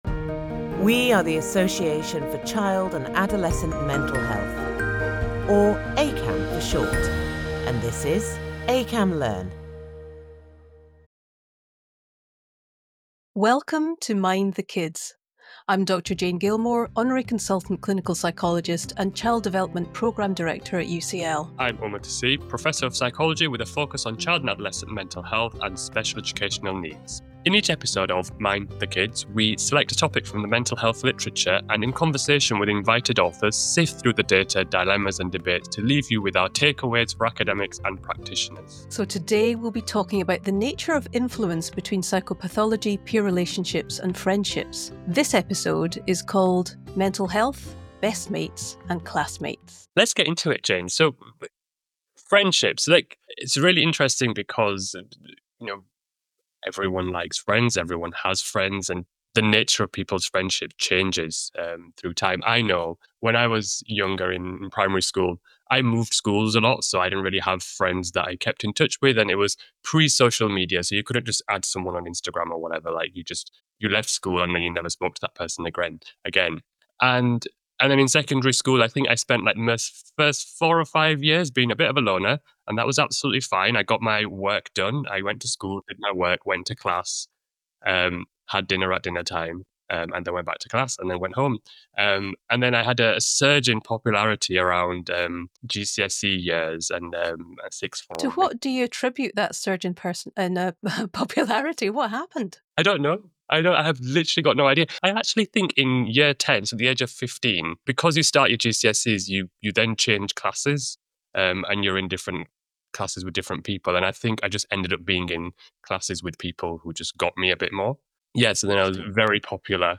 From the playground to the classroom, the trio explores how friendships evolve, why quality matters more than quantity, and how popularity can shape – or sometimes complicate – the social landscape. They discuss the complex, two-way relationship between mental health and friendships, revealing how emotional struggles can make it harder to connect, while strong friendships can buffer against adversity. The conversation also highlights the importance of understanding cultural differences in friendship dynamics and why interventions that put mental health first can lead to better social outcomes.